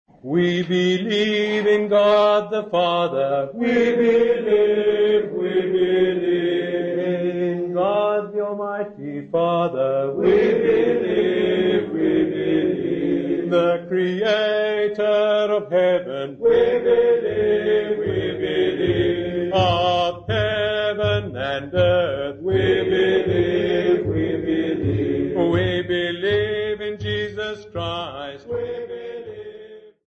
Workshop participants
Folk music Africa
Sacred music South Africa
Field recordings South Africa
A hymn sung as part of a group composition workshop, accompanied by Uhadi bow and drum.